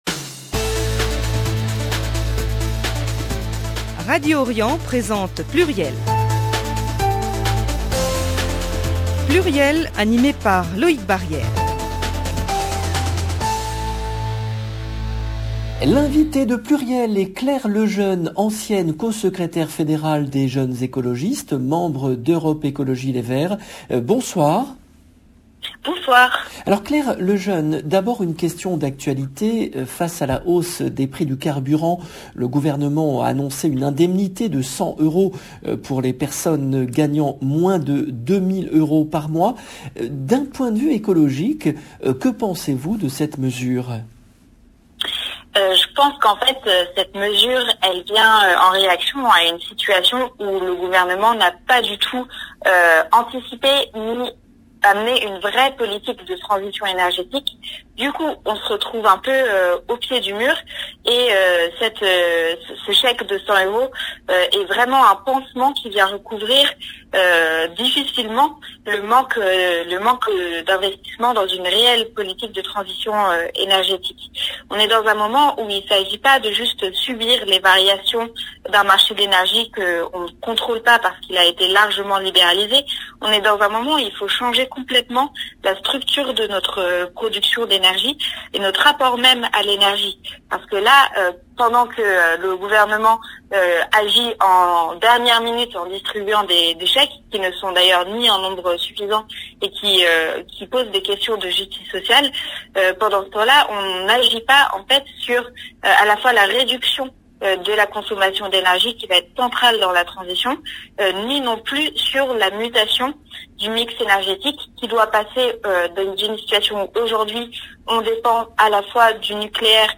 PLURIEL, émission diffusée le mardi 26 octobre 2021